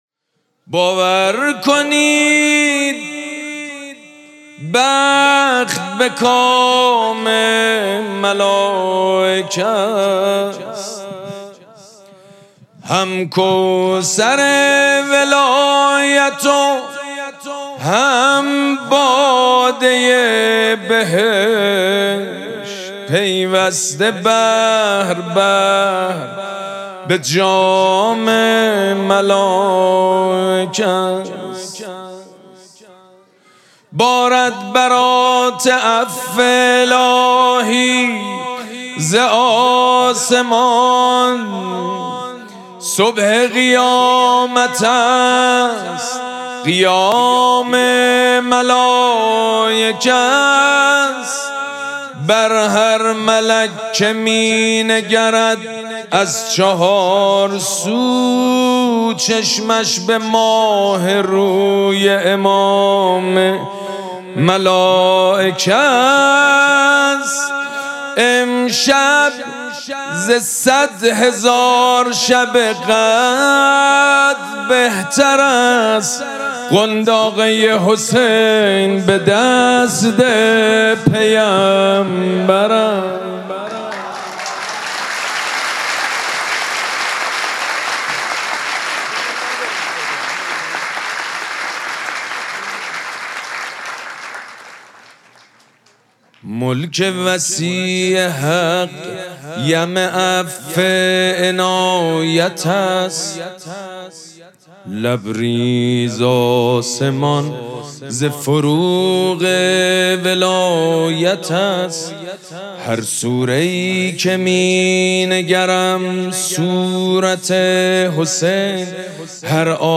شب اول مراسم جشن ولادت سرداران کربلا
مدح
حاج سید مجید بنی فاطمه